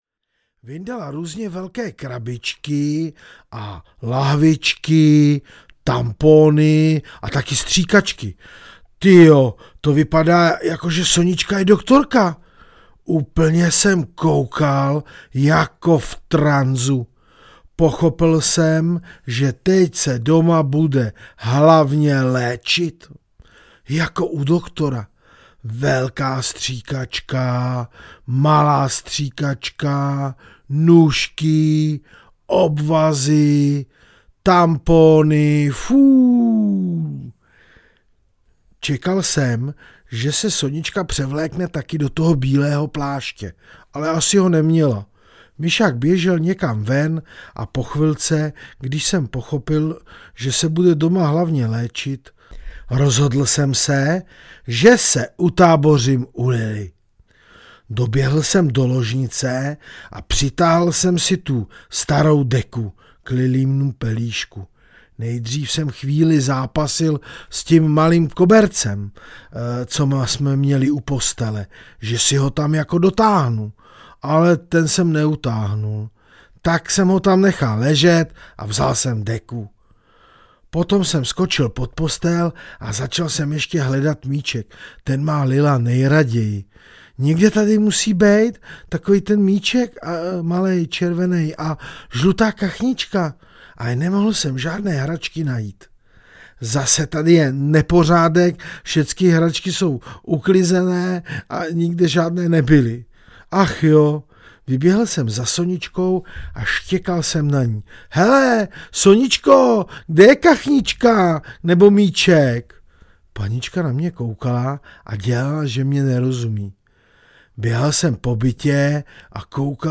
Pohádky z Piešťan a Vrzavky II. audiokniha
Ukázka z knihy